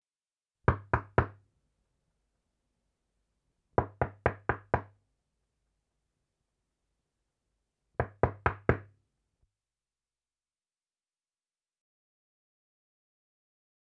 זקוקה לאפקט של דפיקה בדלת
נקישות בדלת.WAV